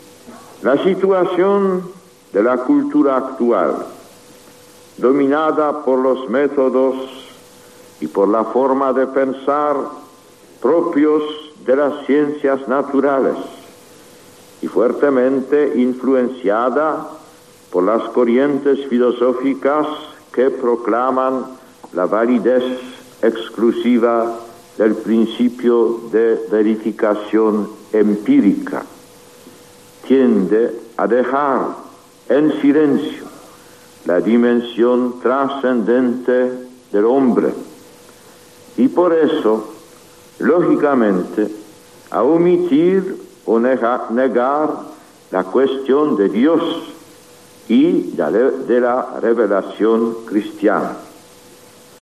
Discurso del Papa Juan Pablo II a los profesores de teología de la Universidad Pontificia de Salamanca